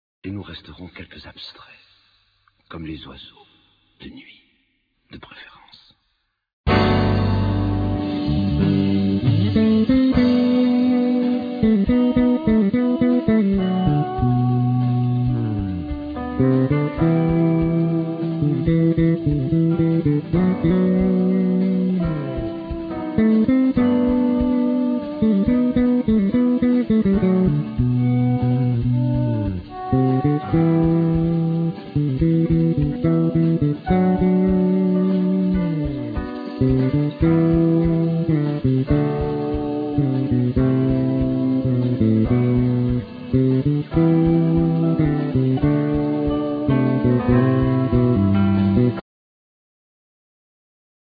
Bass
Keyboards
Trumpet
Guitar
Drums
Percussions
Trombone